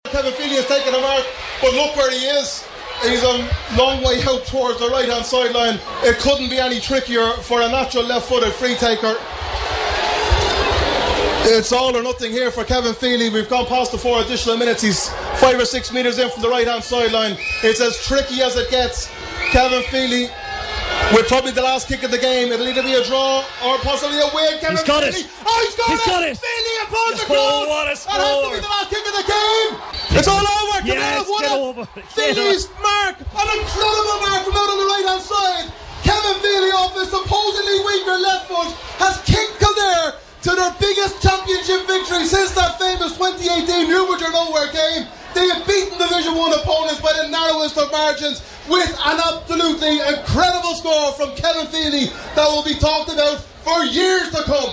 Commentary
Relive that moment with our commentary team